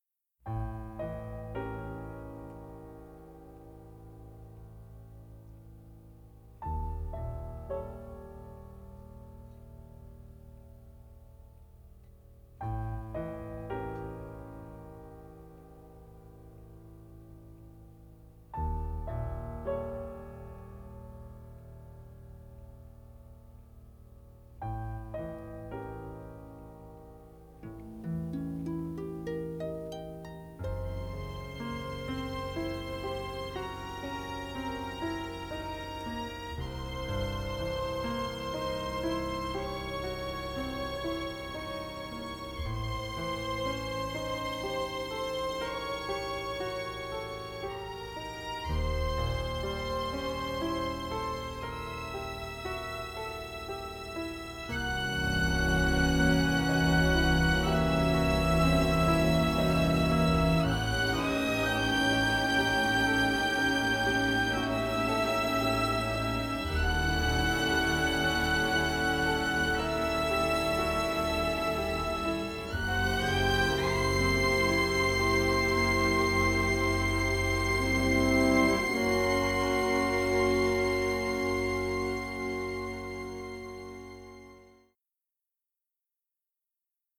Dynamic orchestral score